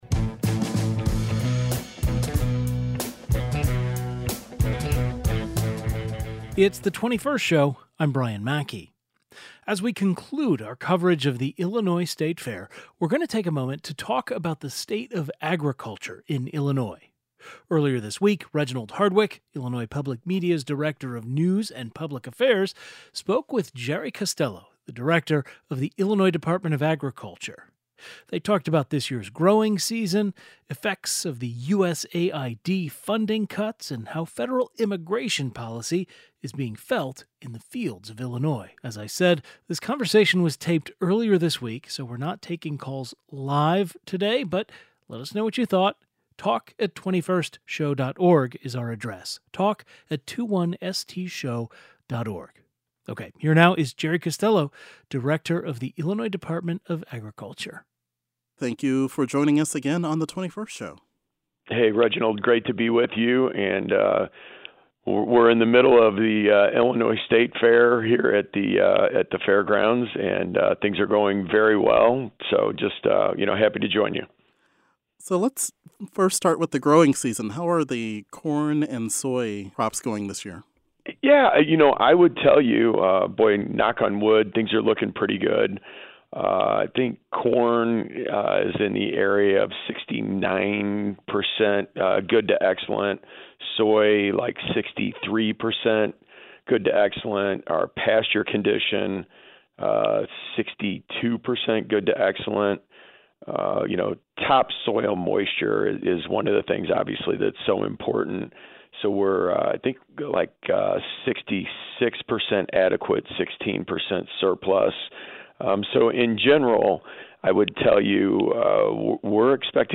Guest: Jerry Costello Director, Illinois Department of Agriculture Tags jerry costello agriculture illinois department of agriculture